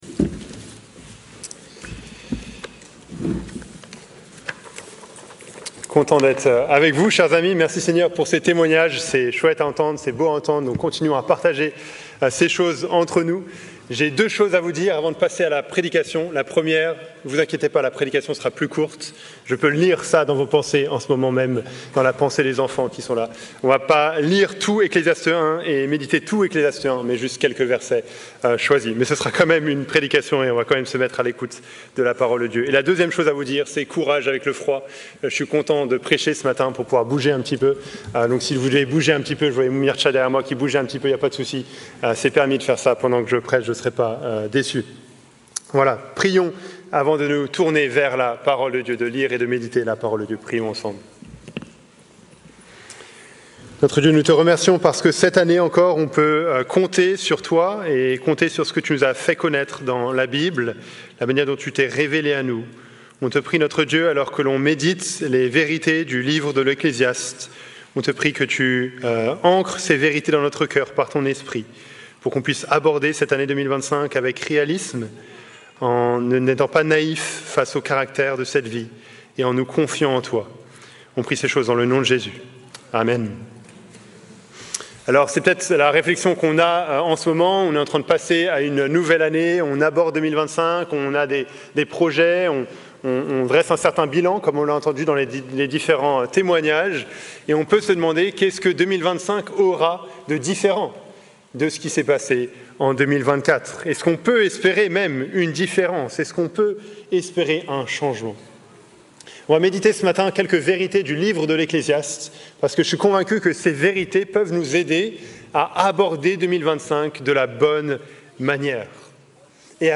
Predication-05-01.mp3